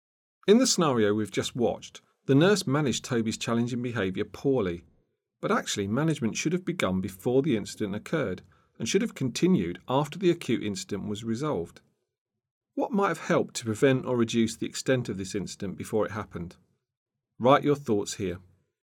Narration audio (MP4)